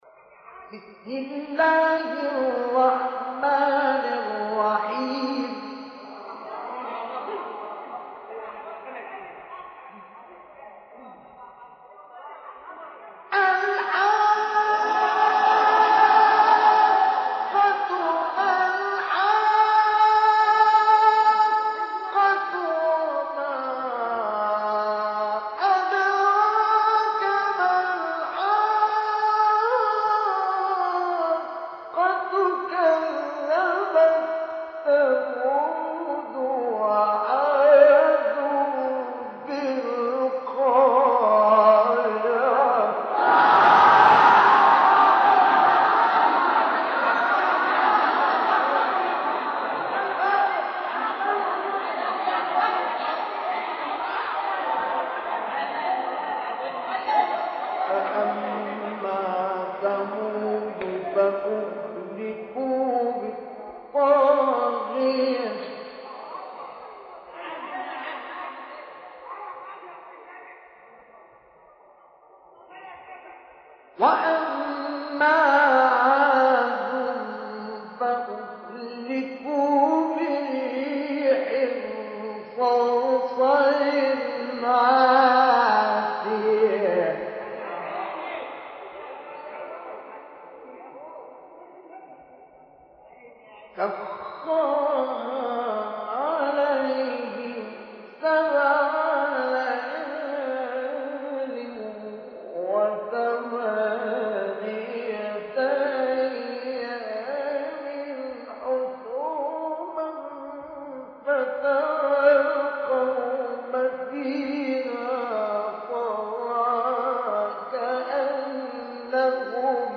تلاوت آیات ابتدایی سوره حاقه توسط استاد مصطفی اسماعیل | نغمات قرآن | دانلود تلاوت قرآن